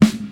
• '90s Studio Rap Snare Sample A Key 27.wav
Royality free snare drum sample tuned to the A note. Loudest frequency: 602Hz
90s-studio-rap-snare-sample-a-key-27-Pfl.wav